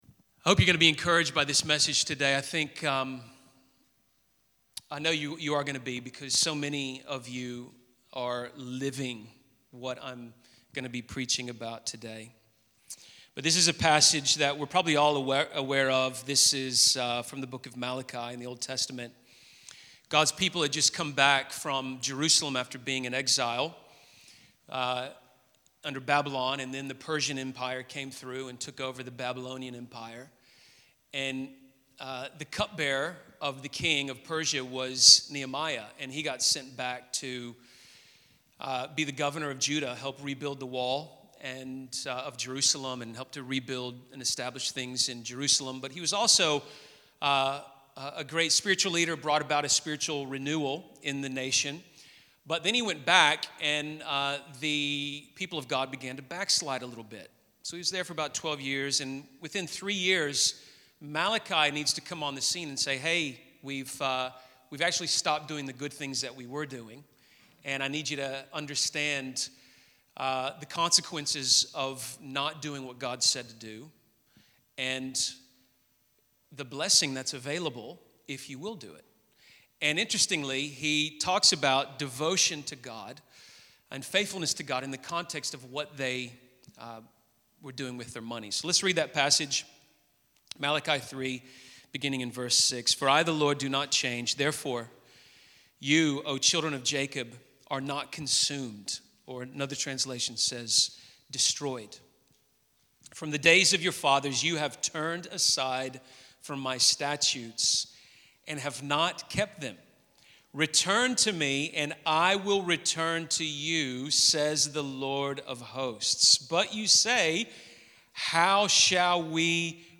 In this second message in our Jesus Over Money series, we look at Malachi 3. God calls out the people of Israel for how they have turned away from God.